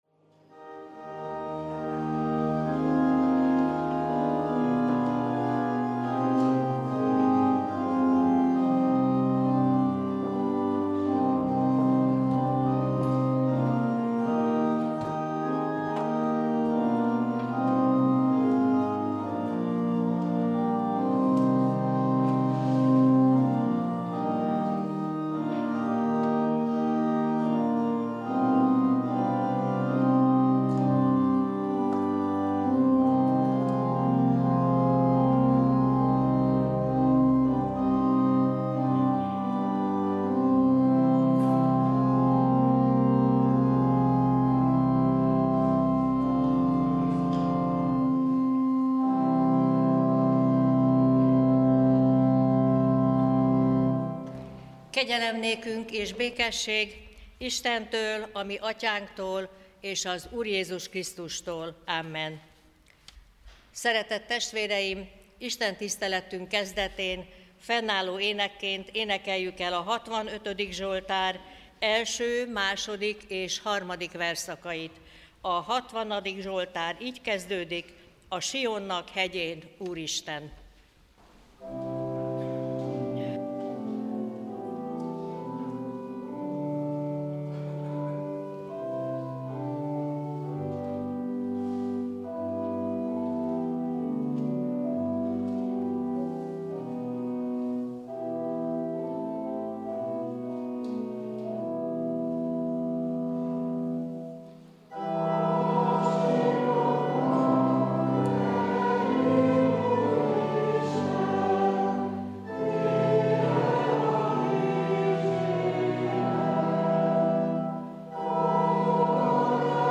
Prédikációk 2025